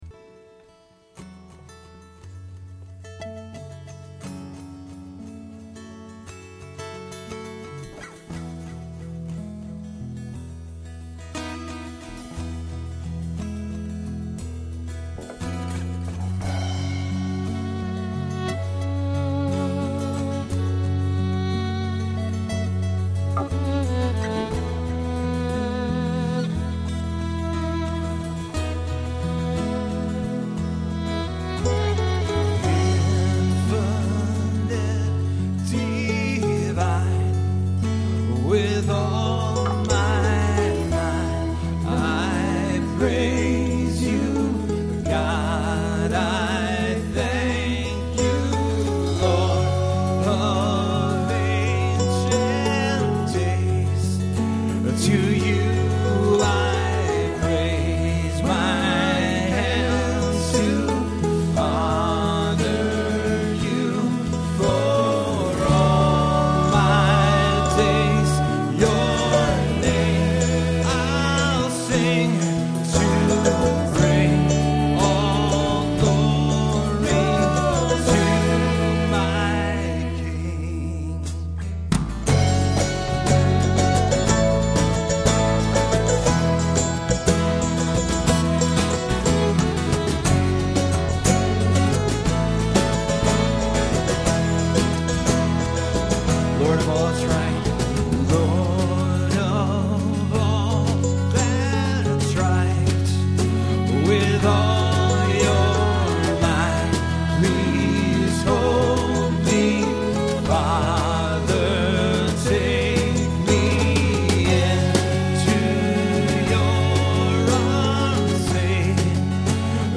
Performed live at Terra Nova - Troy on 11/9/08.